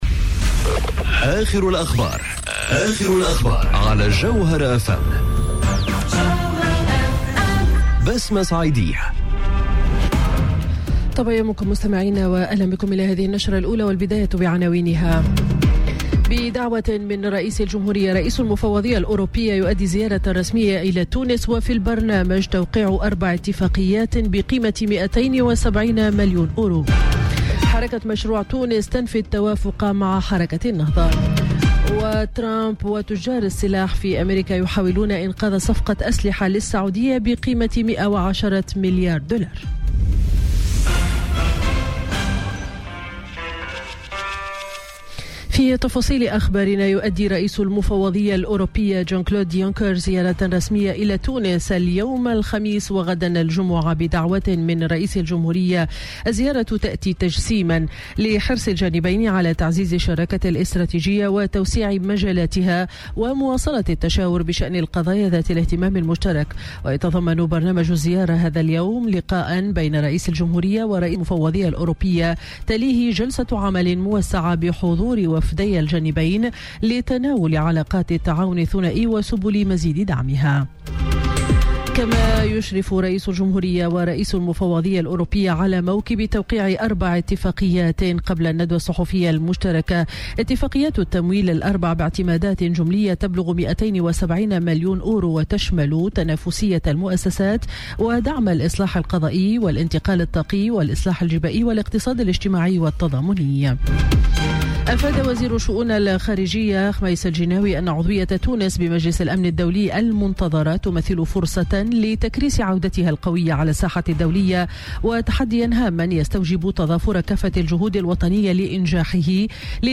نشرة أخبار السابعة صباحا ليوم الخميس 25 أكتوبر 2018